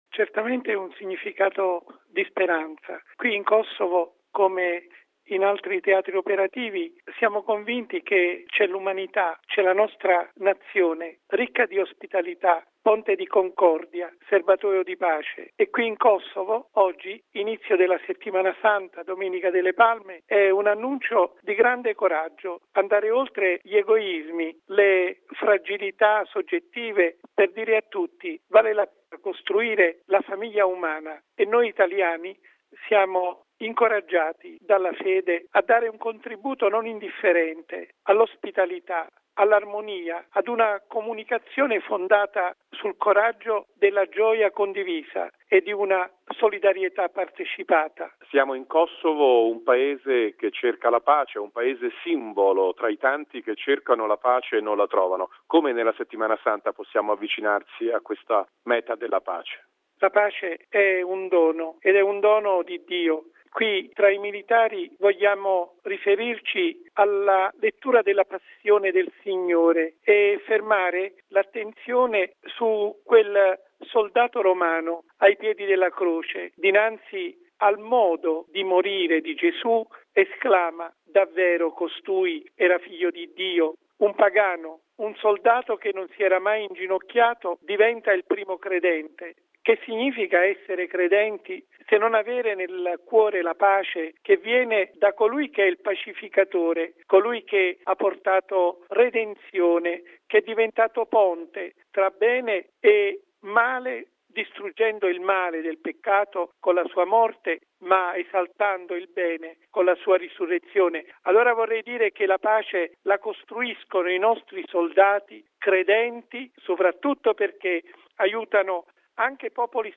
L’Ordinario militare per l’Italia, mons. Vincenzo Pelvi, è a Gjakova, in Kosovo, dove stamani ha celebrato la Messa alla presenza dei militari della missione Kfor, di esponenti della Chiesa locale e personalità della comunità kosovara.